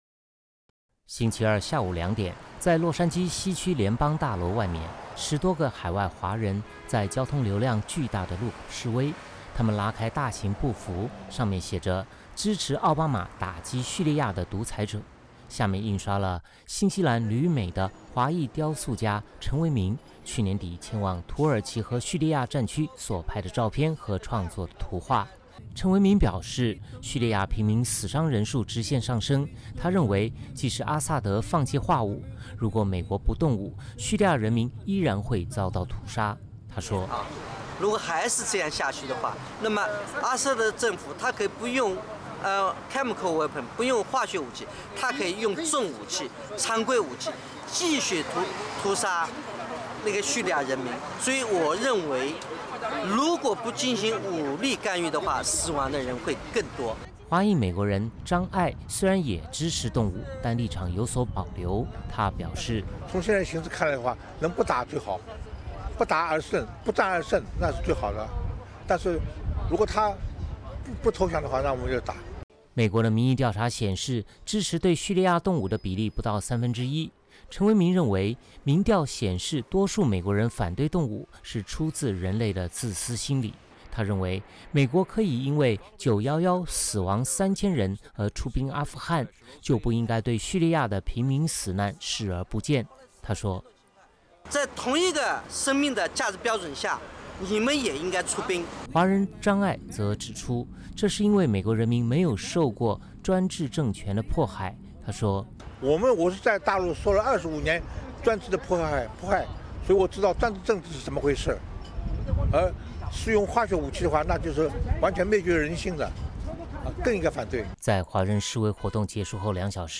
*华人走上街头击战鼓*
许多路过的车辆按喇叭表示声援，相形之下，在交通流量更大的联邦大楼前的赞成动武的示威，显得安静的多，这和美国当前反战民意占上风的形势若合符节。